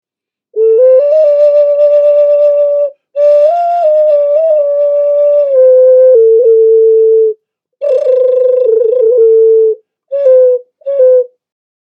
Gator Ocarina hand Painted black pottery produces lovely melody
It is natural tuned and produces a lovely melody.
A recording of the sound of this particular ocarina is in the top description, just click on the play icon to hear the sound.
It has 4 key holes.